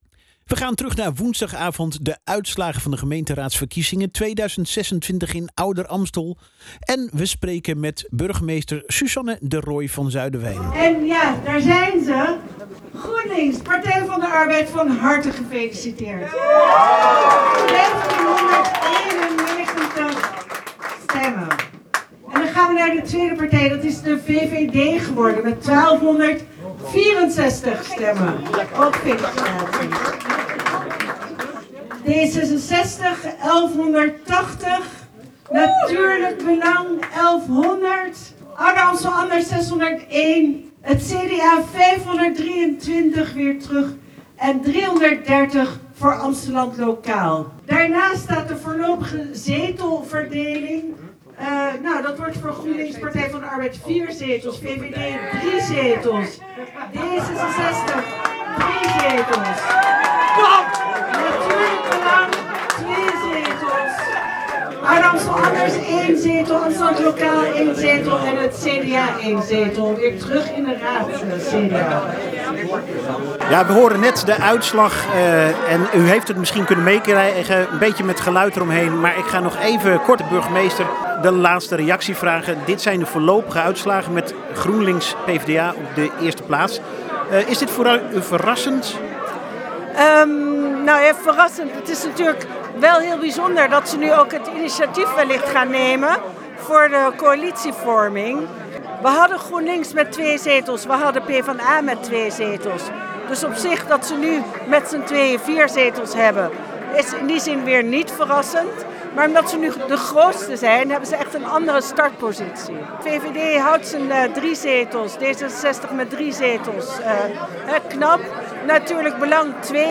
Ouder-Amstel – Tijdens een drukbezochte verkiezingsavond in het gemeentehuis heeft Jammfm woensdagavond live verslag gedaan van de uitslagen van de gemeenteraadsverkiezingen 2026. In gesprek met burgemeester Susanne de Rooij van Zuiderwijk werd duidelijk dat de politieke verhoudingen in de gemeente flink zijn verschoven.